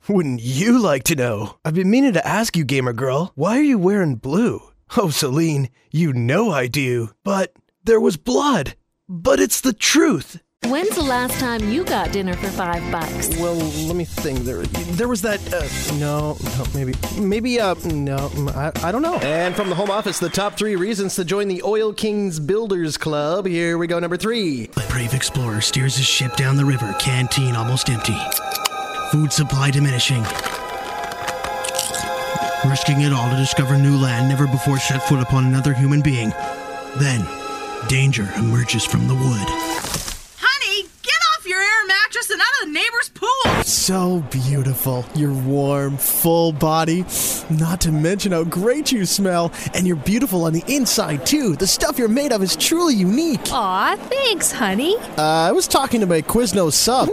Male
Character / Cartoon
Character/Animation